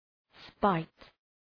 spite.mp3